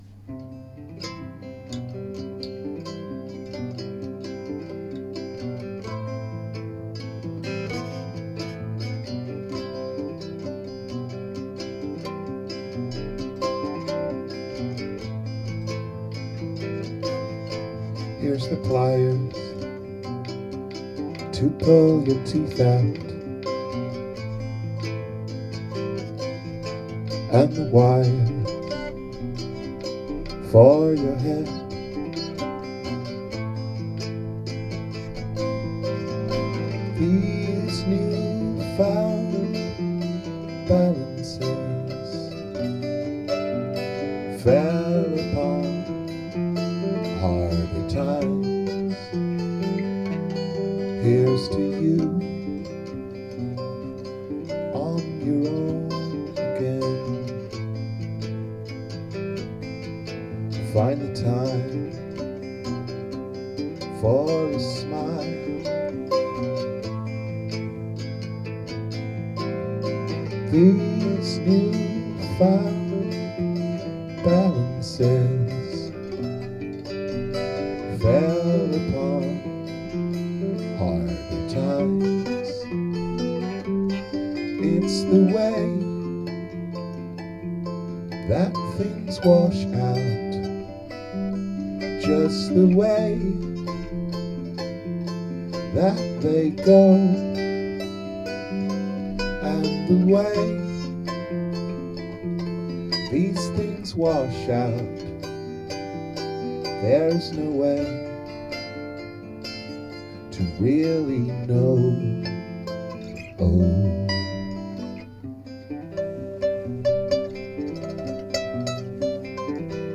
Download Wash Out - rough sketch rehearsal 9.2.2012